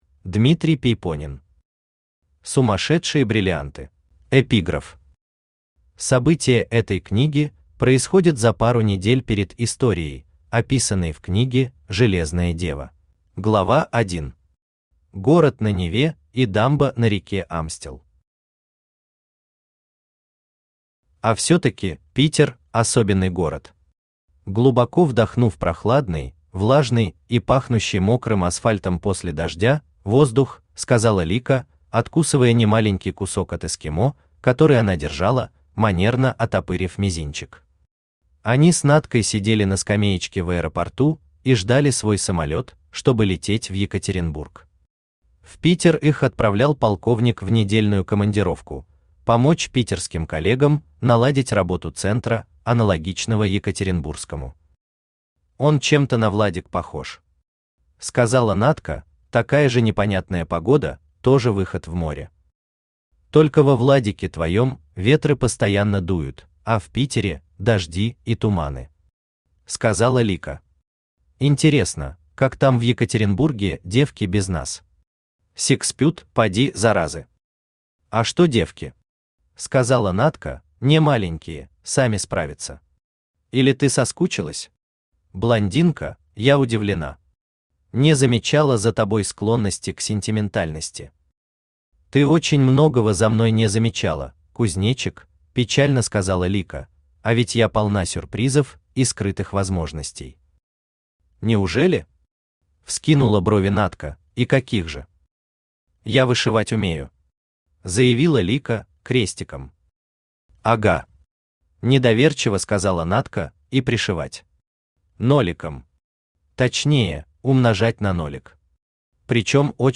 Аудиокнига Сумасшедшие бриллианты | Библиотека аудиокниг
Aудиокнига Сумасшедшие бриллианты Автор Дмитрий Пейпонен Читает аудиокнигу Авточтец ЛитРес.